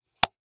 Тихий хлопок